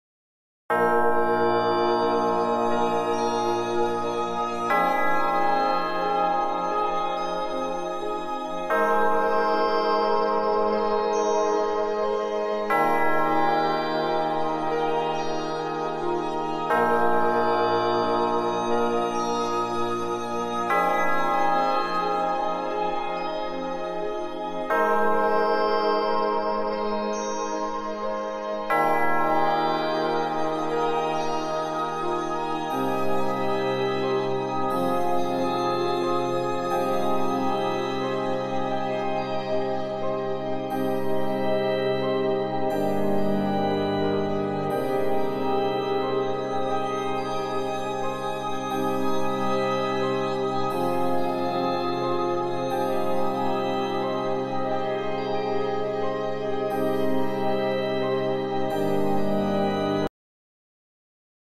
Frequencia 659hz Atraía dinheiro sound effects free download